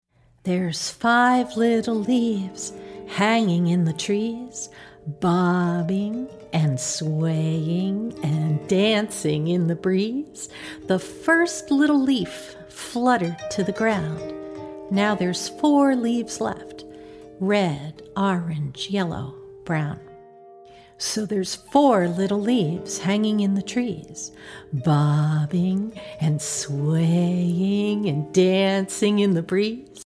A wonderfully engaging fingerplay!